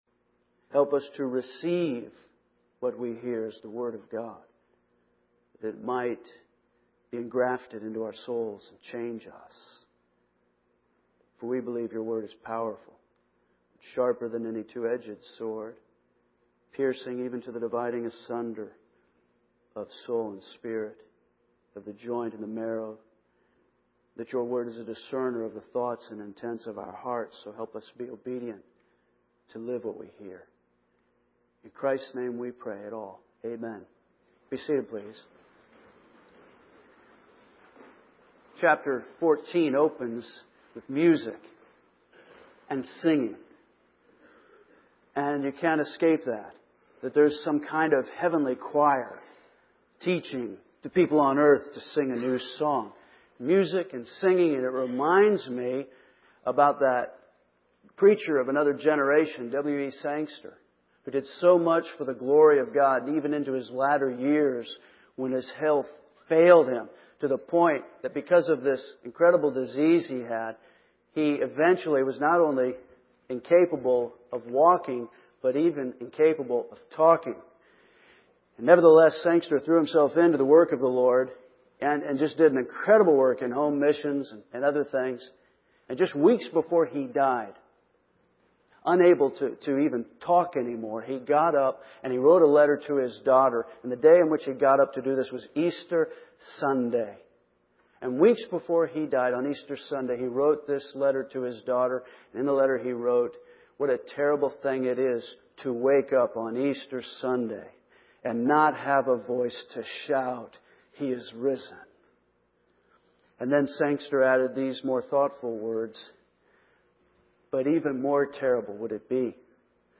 By the way, you should have received a handout this morning as you entered the sanctuary.